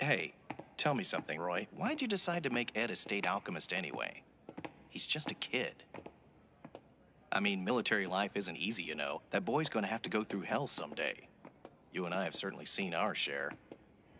Small Dialogue Between State Alchemists.wav